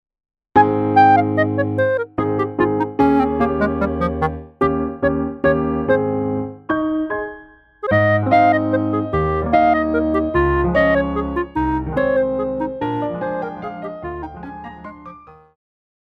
Classical
Clarinet-Bb
Piano
Etudes
Solo with accompaniment